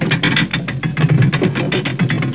winch2.mp3